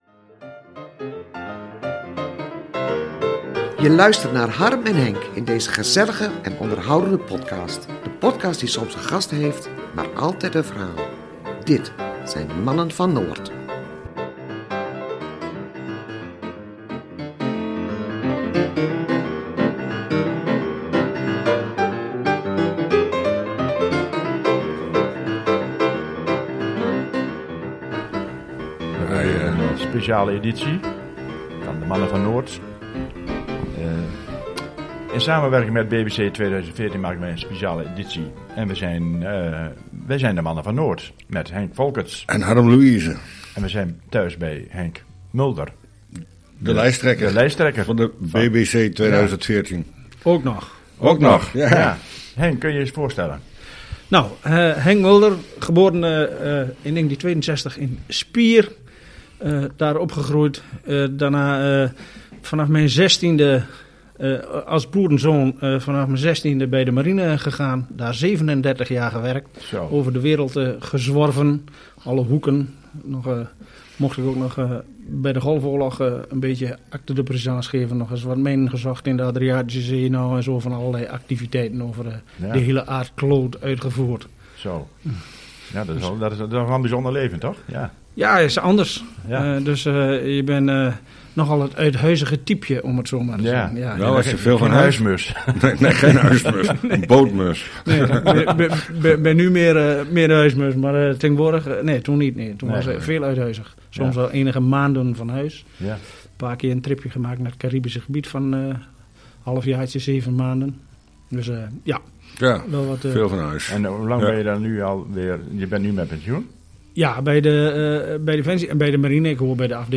In deze reeks stellen we een aantal kandidaat-gemeenteraadsleden aan jullie voor. We gaan met hen in gesprek, en proberen te ontdekken wie zij zijn. Wat drijft hen om zich verkiesbaar te stellen en wat willen ze gaan betekenen voor Coevorden en het buitengebied?